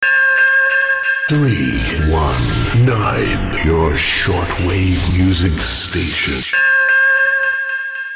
0821 some music, just below high noise level (via Weston SDR)
0823 better via Weston SDR: music, jingle 'music power', pop
Balanced wire antennas, wire lines and ATU